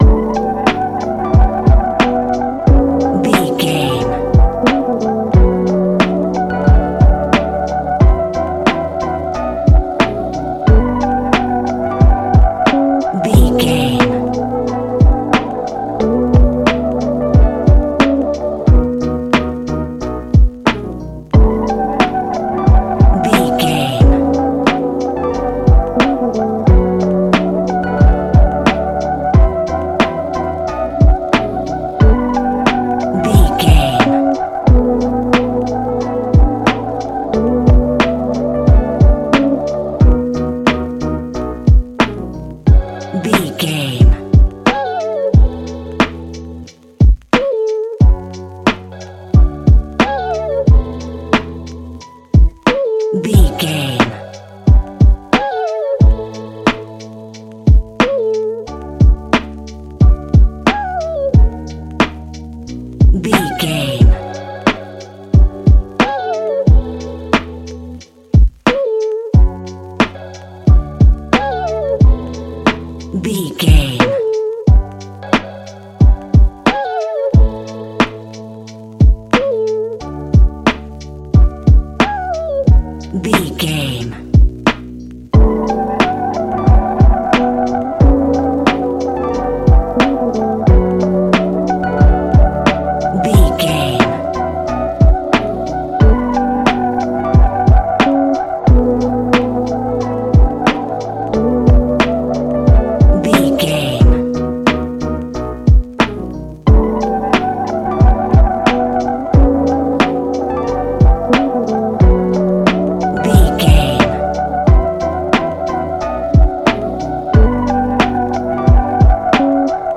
Ionian/Major
D♭
laid back
Lounge
sparse
new age
chilled electronica
ambient
atmospheric
morphing
instrumentals